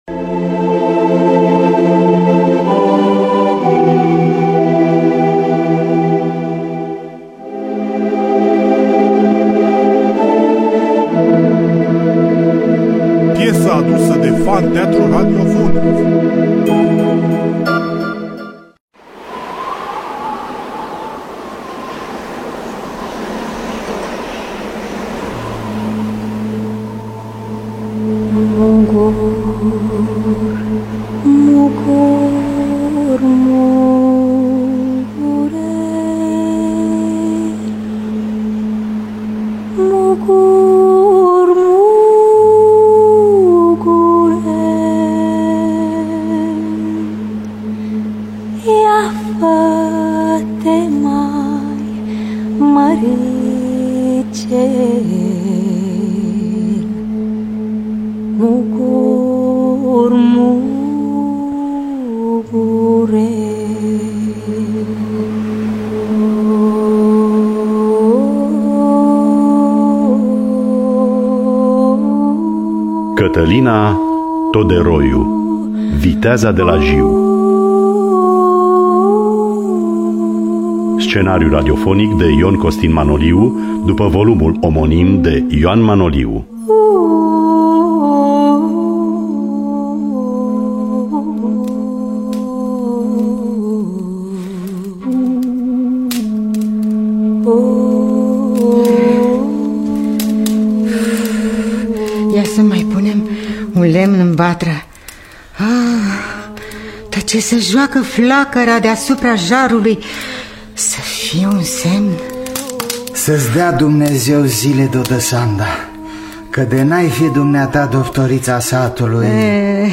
Scenariu radiofonic
Cântece pe teme populare